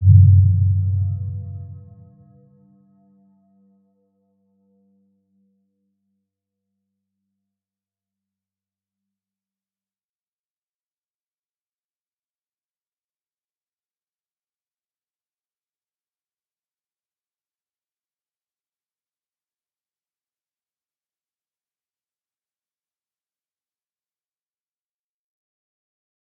Round-Bell-G2-p.wav